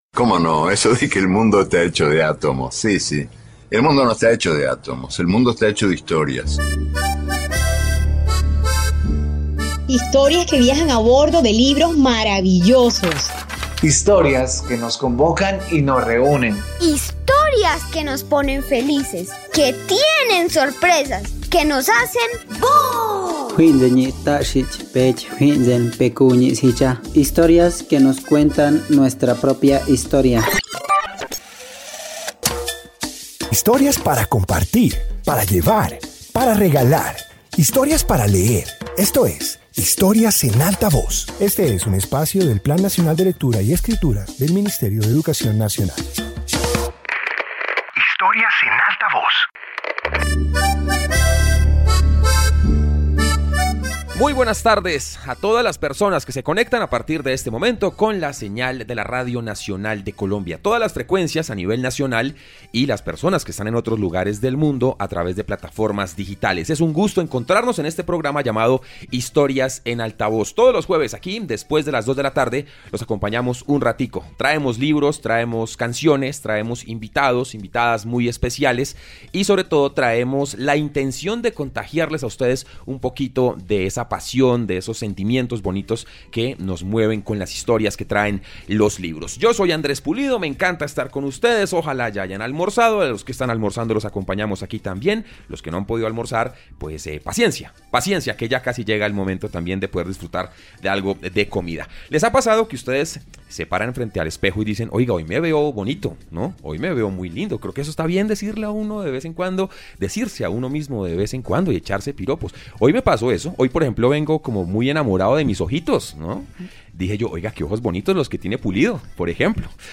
Introducción Este episodio de radio presenta historias relacionadas con el cuerpo humano. Comparte relatos que muestran sus funciones, simbolismos y la manera en que aparece en distintos textos literarios.